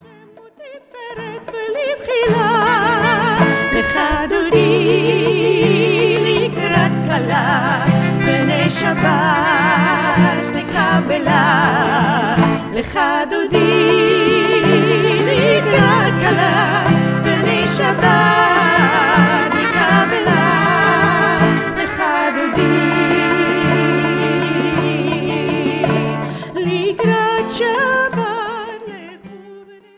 Featuring studio recordings of folk